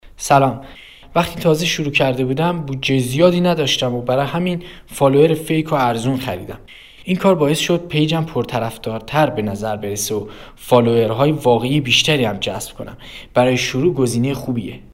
برای اطمینان بیشتر و آشنایی با تجربیات مثبت دیگران، به نظرات و صدای رضایت آنها گوش دهید و پیش از خرید، با تجربه‌های واقعی خریداران سرویس فالوور فیک آوپنل آشنا شوید.